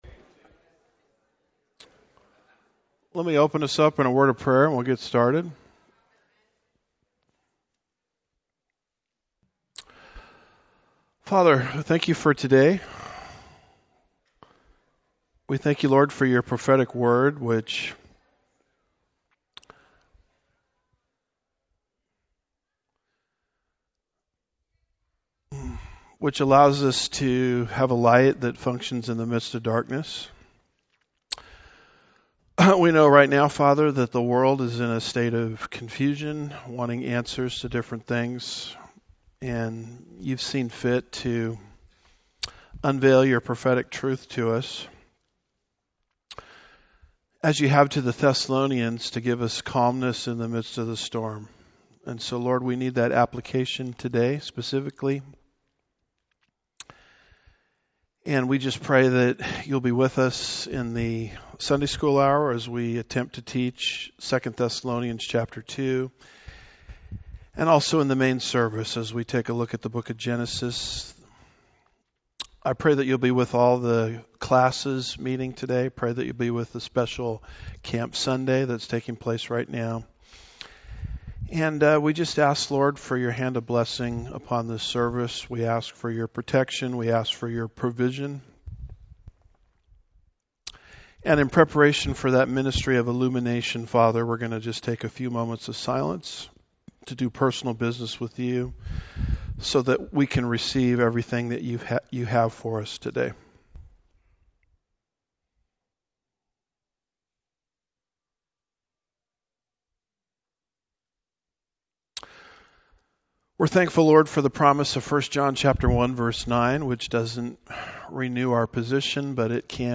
Home / Sermons / Iran Attacks Israel - How to Respond?